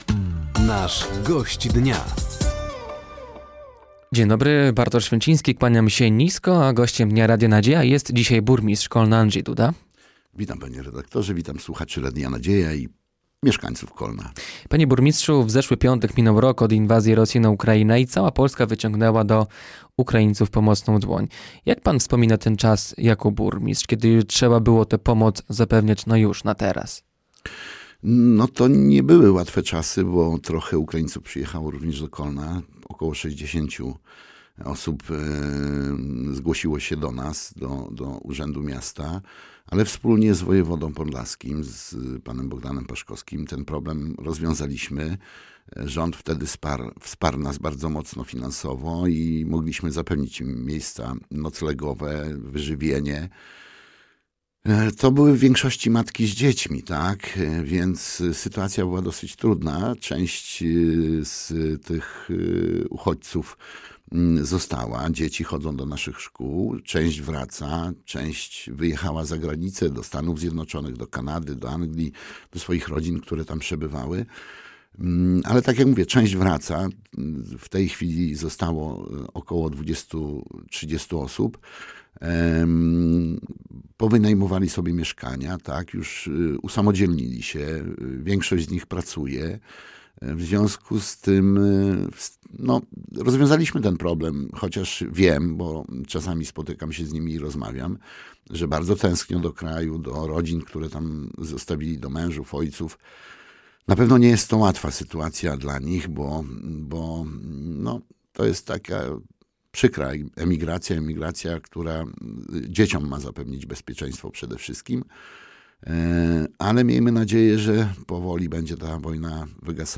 Gościem Dnia Radia Nadzieja był dzisiaj burmistrz Kolna, Andrzej Duda. Tematem rozmowy była między innymi rocznica inwazji Rosji na Ukrainę, koszty energii i modernizacja miejskiej ciepłowni.